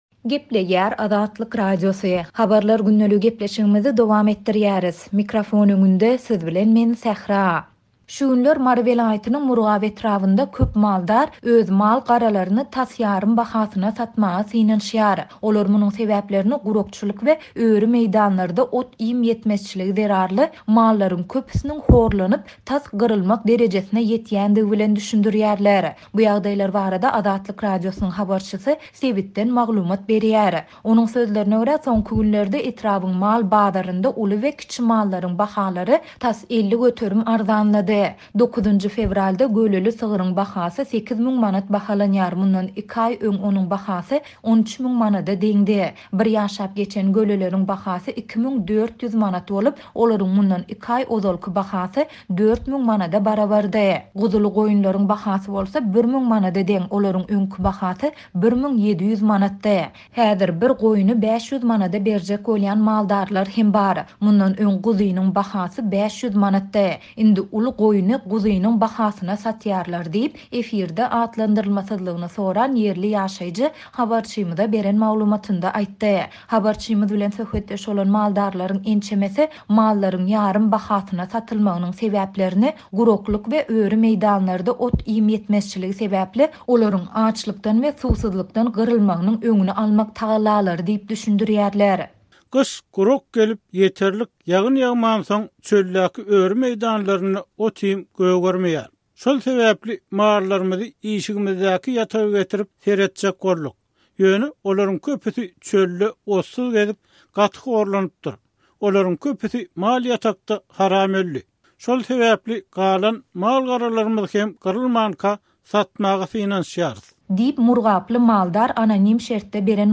Bu ýagdaýlar barada Azatlyk Radiosynyň habarçysy sebitden maglumat berýär.